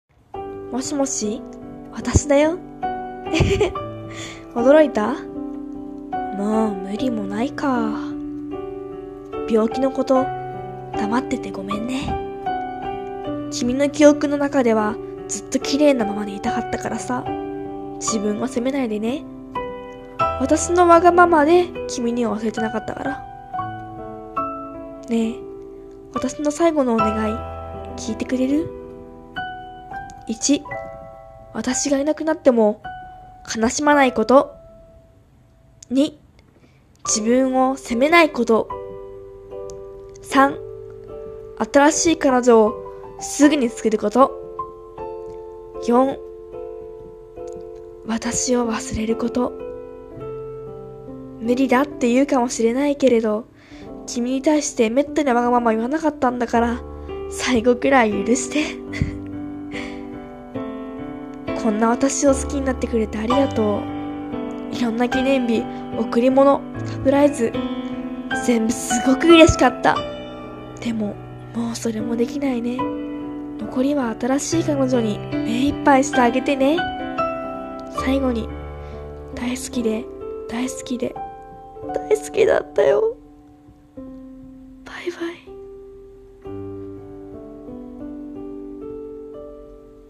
【一人声劇】最後の願い【切ない台本】